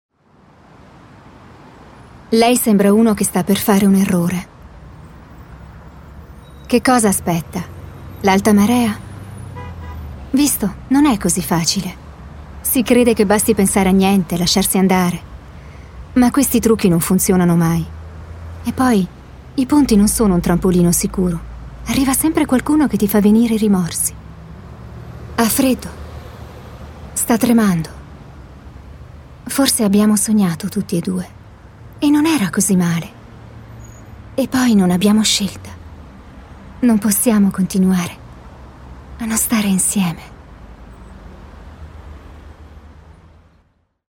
Sprechprobe: Industrie (Muttersprache):
I am an educated actress and I speak with a broad italian accent, what makes my speach very clear and articulately.
I have a joung, beautiful and light voice.